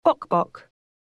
영국 [bɔ́kbɔ̀k]